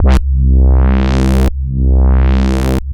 Roland A C2.wav